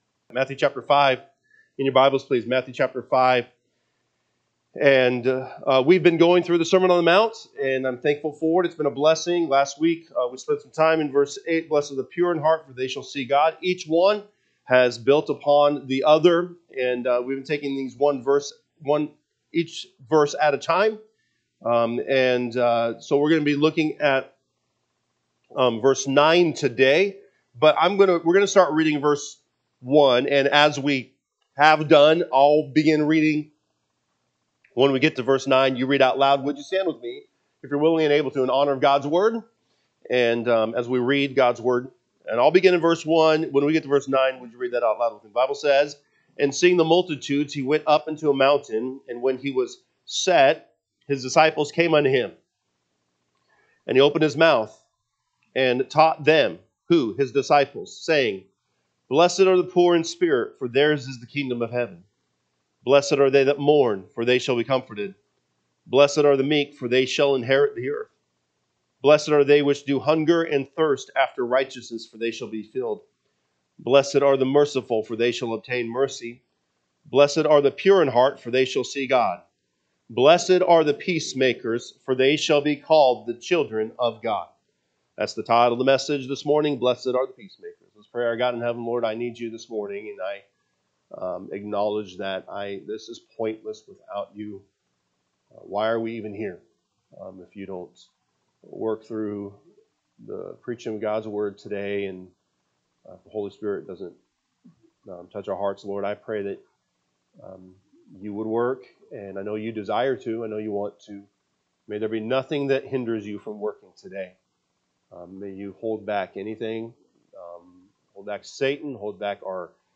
March 8, 2026 am Service Matthew 5:1-9 (KJB) 5 And seeing the multitudes, he went up into a mountain: and when he was set, his disciples came unto him: 2 And he opened his mouth, and taug…